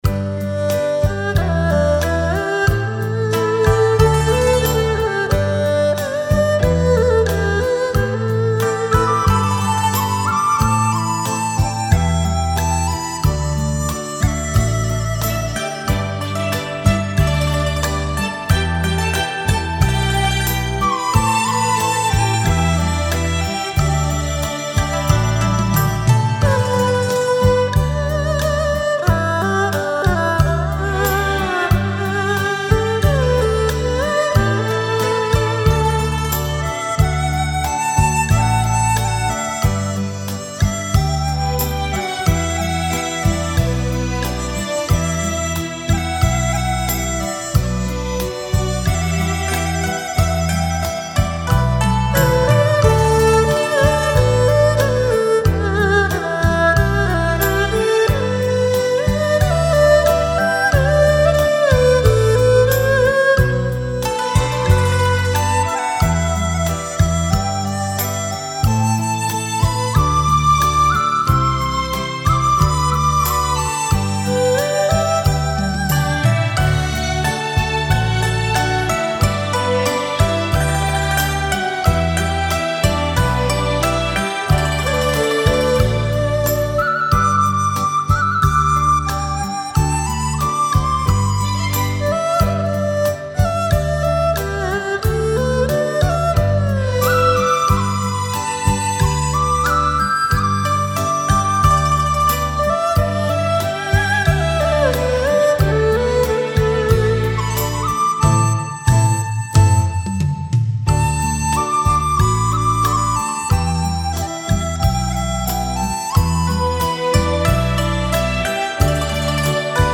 悠扬舒缓的音乐